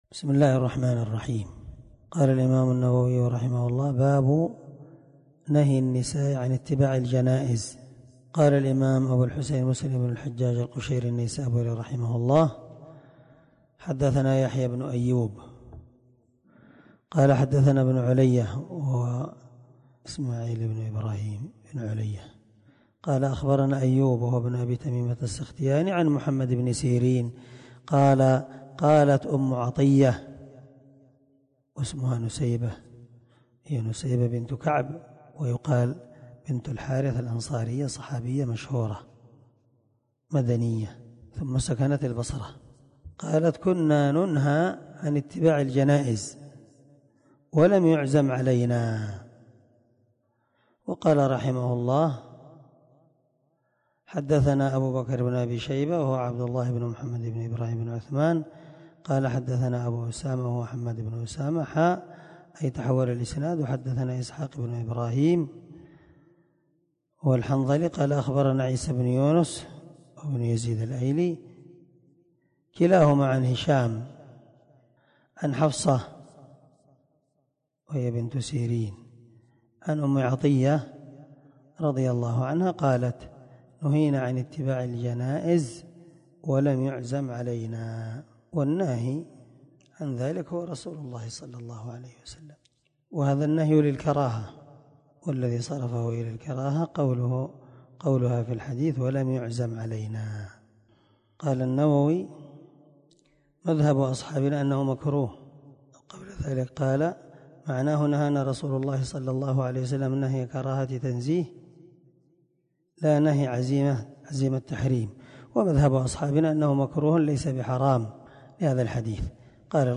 • سلسلة_الدروس_العلمية
• ✒ دار الحديث- المَحاوِلة- الصبيحة.